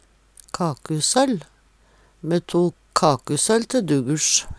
kakusøll soll laga med vanleg brød Eintal ubunde Eintal bunde Fleirtal ubunde Fleirtal bunde søll søll`n søllar søllan Eksempel på bruk Me tok kakusøll te dugursj. Sjå òg brøsøll (Veggli) Høyr på uttala Ordklasse: Substantiv hokjønn Kategori: Hushald, mat, drikke Attende til søk